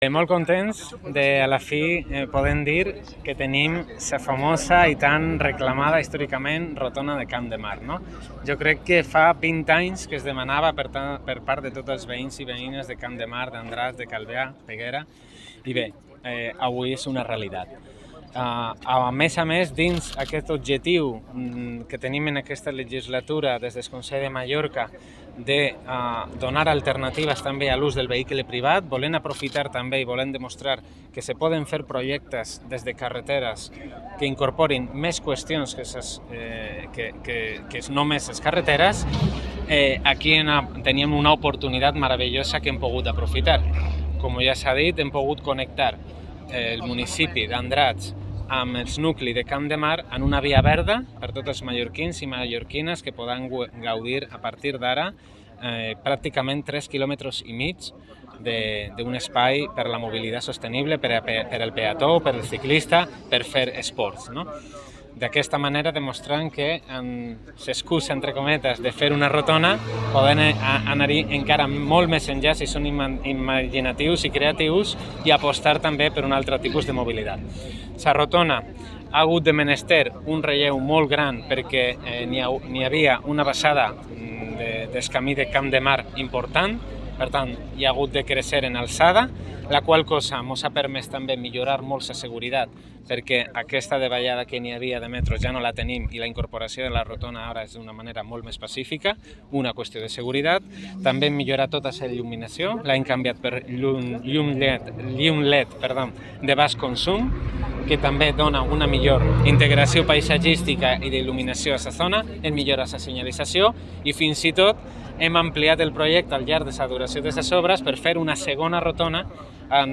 Declaracions Catalina Cladera
Declaracions